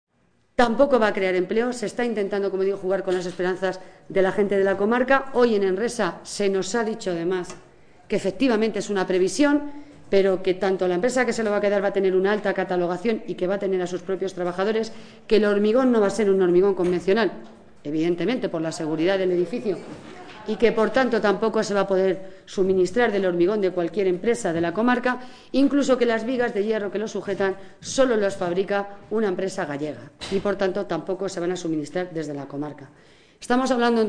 Así lo ha expresado esta tarde en las Cortes regionales, en una comparecencia ante los medios de comunicación, una de las alcaldesas presentes en ese encuentro, Angustias Alcázar, primer edil del municipio conquense de Belmonte.
Cortes de audio de la rueda de prensa